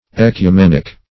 Ecumenic \Ec`u*men"ic\, Ecumenical \Ec`u*men"ic*al\, a. [L.